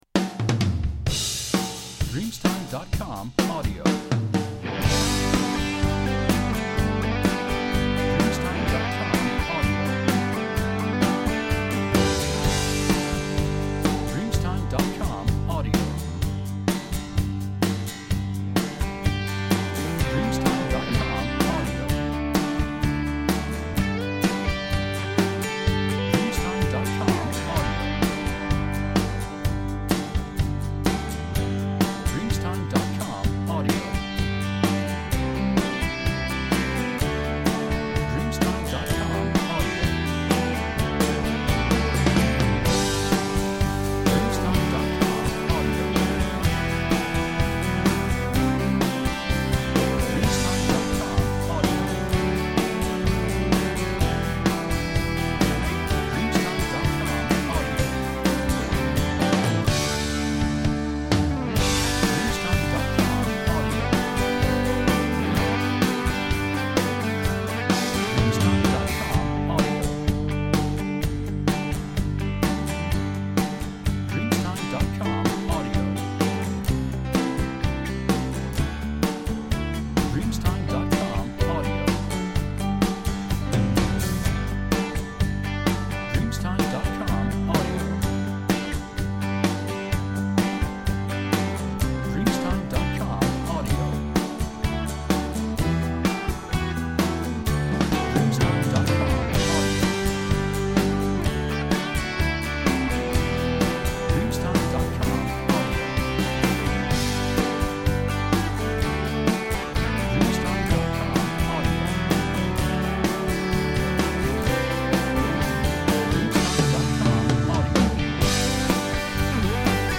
Uptempo Country Instrumental